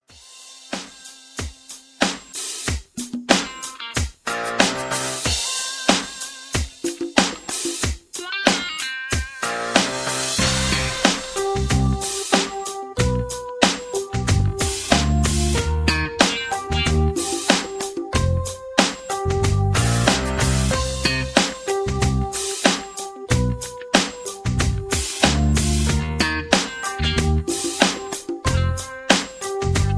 karaoke, studio tracks, sound tracks, backing tracks